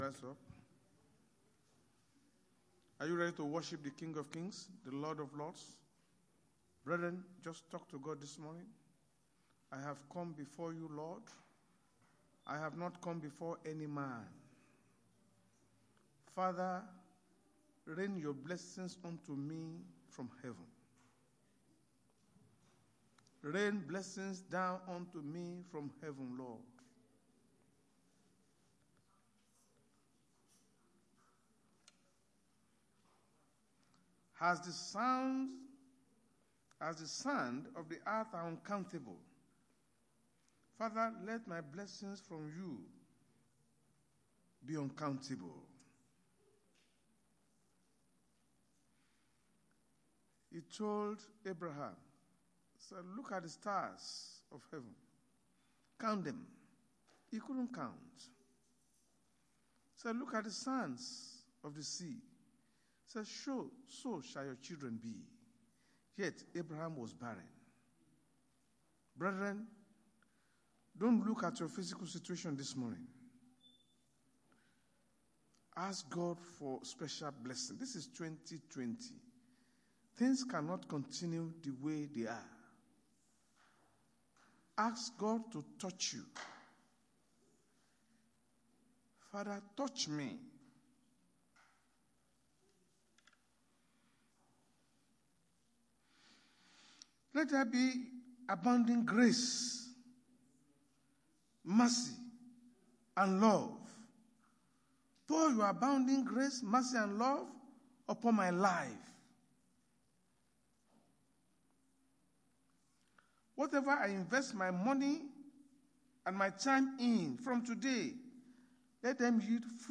Sunday Sermon: The Lord Bless You
Service Type: Sunday Church Service